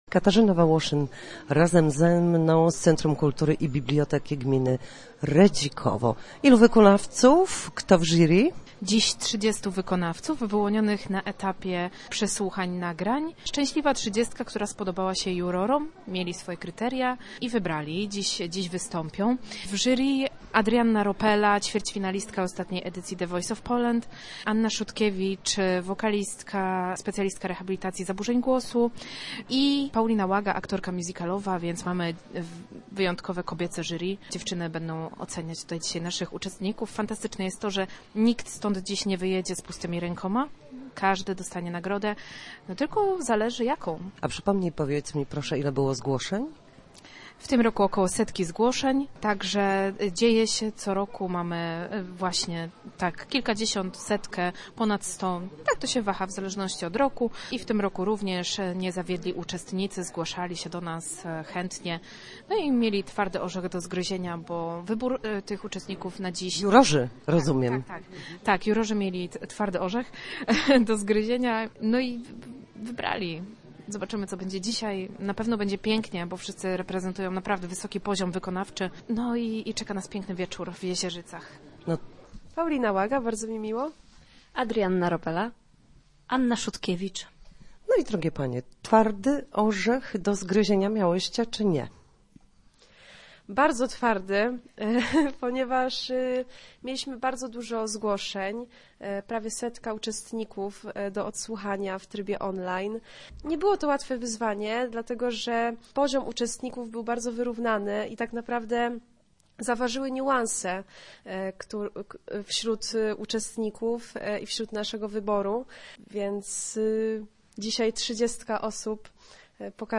W sobotę, 18 stycznia, odbył się XXIV Festiwal Kolęd i Pastorałek w Jezierzycach organizowany przez Centrum Kultury i Bibliotekę Publiczną Gminy Redzikowo. O laur zwycięstwa walczyło 30 wykonawców z różnych części Polski.